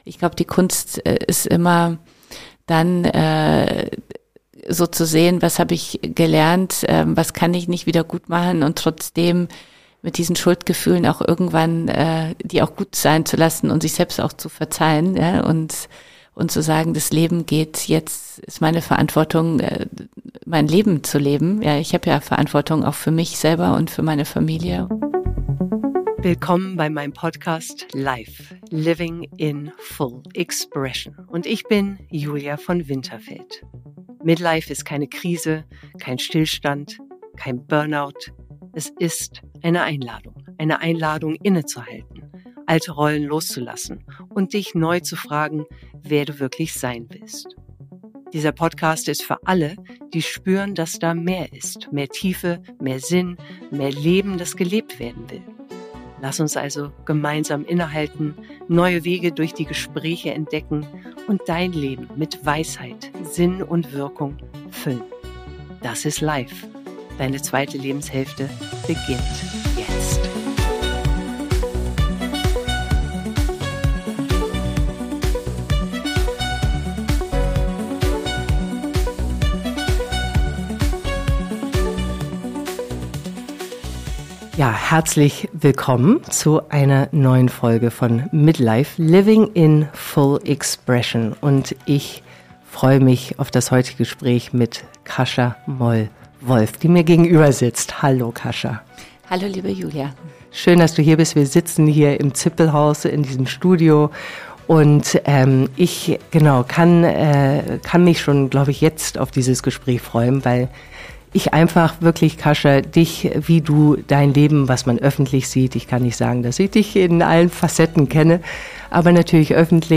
Ein Gespräch über den Mut, loszulassen. Über das Scheitern, das uns lehrt, wer wir wirklich sind. Und über die Kraft, sich selbst neu zu erfinden – jenseits von Rollen, Erwartungen und angepasstem Funktionieren.